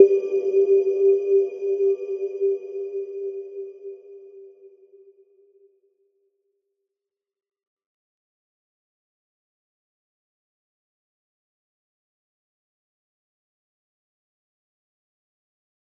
Little-Pluck-G4-f.wav